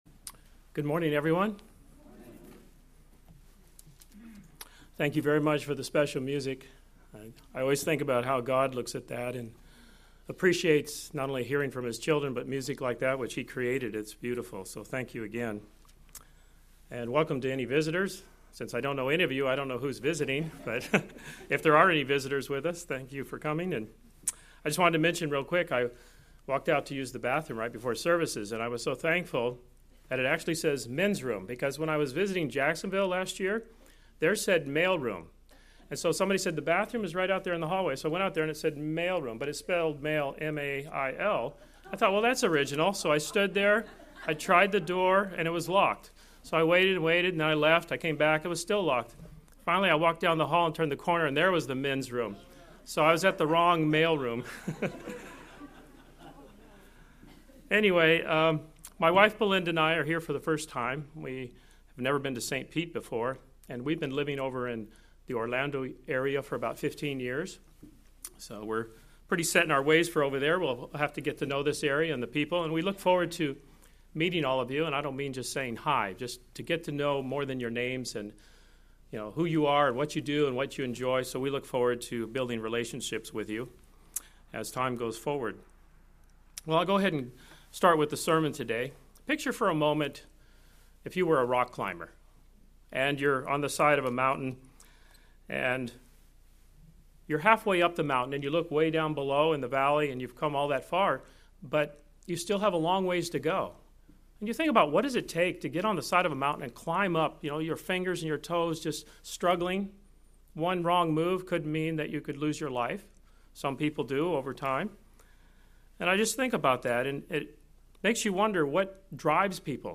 As we listen to this Sermon, lets consider the amount of zeal that God has towards everything He doe's. And as God's Children we have been offered His cloak of zeal as well.